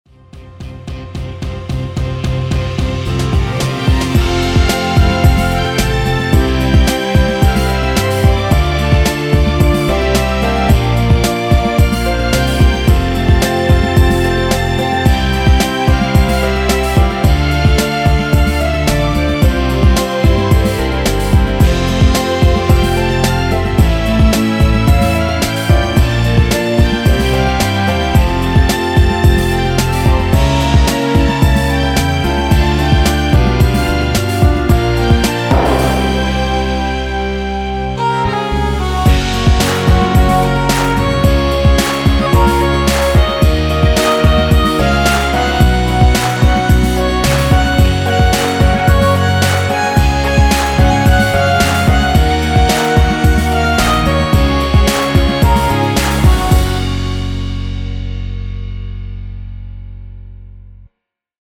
엔딩이 페이드 아웃이라노래 부르시기 편하게
원곡에서 4분 45초까지하고 엔딩을만들어 놓았습니다.(미리듣기 확인)
원키 멜로디 포함된 MR입니다.
앞부분30초, 뒷부분30초씩 편집해서 올려 드리고 있습니다.